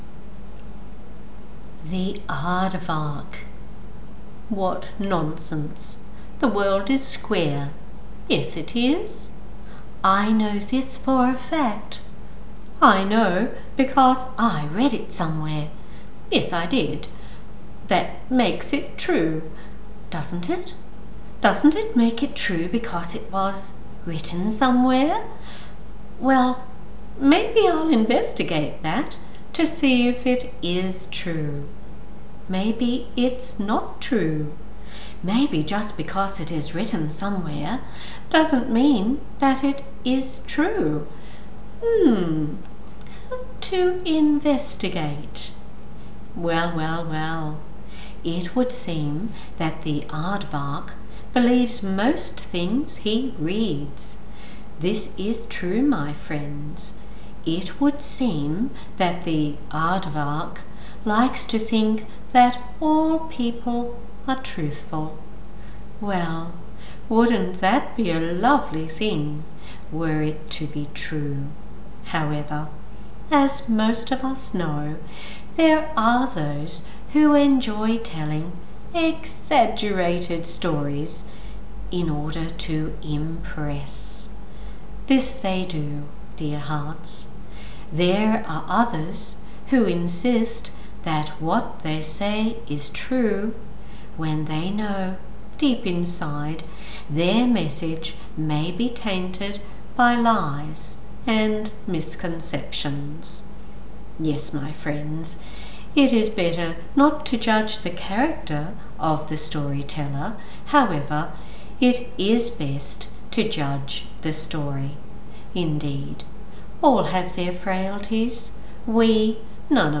I reckon you’d be a wonderful children’s book reader – very alive, expressive.